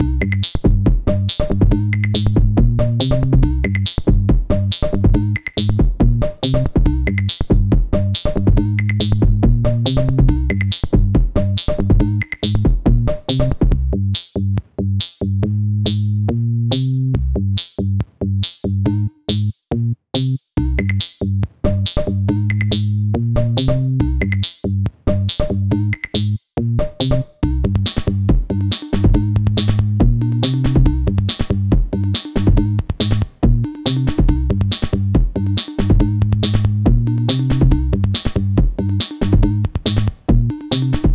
Bucle de Electroclash
Música electrónica
melodía
repetitivo
sintetizador